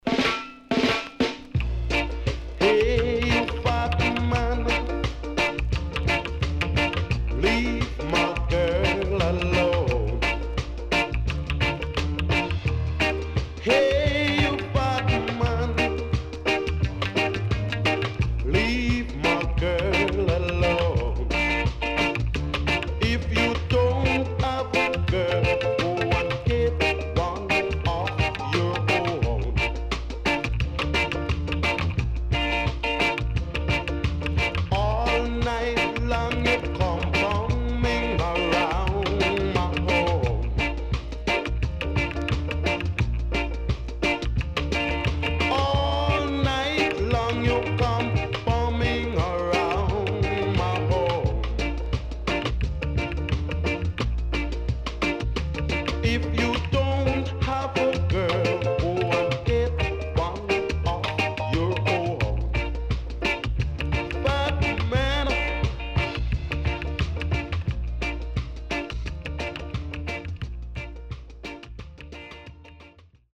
HOME > SKA / ROCKSTEADY  >  ROCKSTEADY
SIDE A:所々耳障りなノイズ入ります。